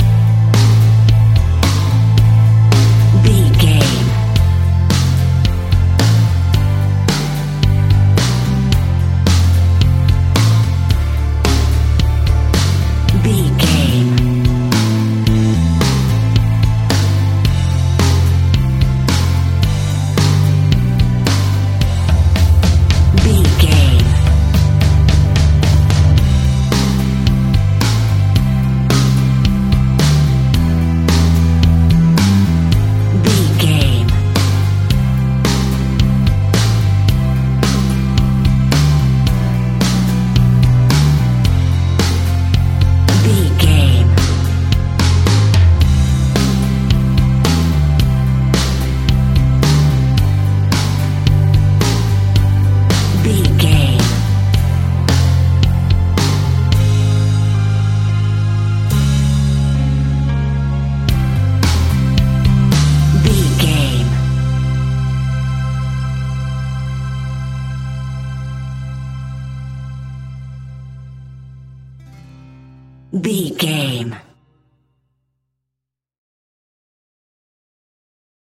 Ionian/Major
calm
melancholic
smooth
uplifting
electric guitar
bass guitar
drums
pop rock
indie pop
organ